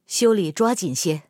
SU-122A小破修理语音.OGG